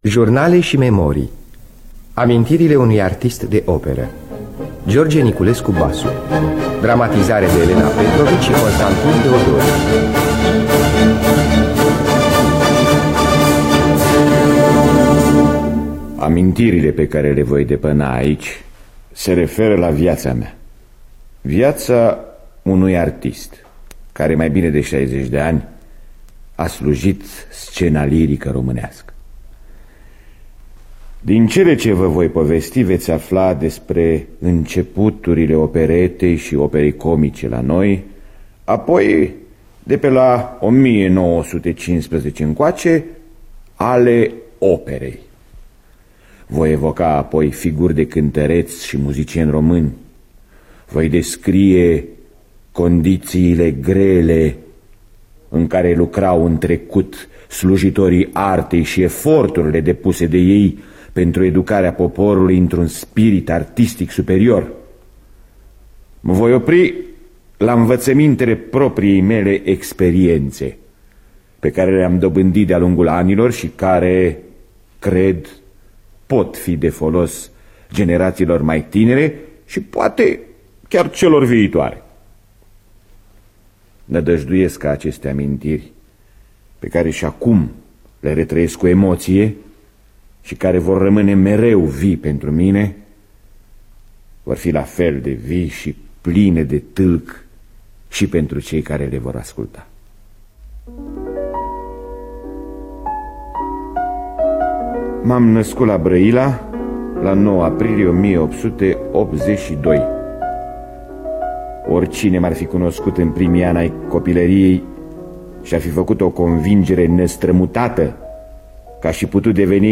Scenariu radiofonic de Elena Petrovici și Constantin Teodori.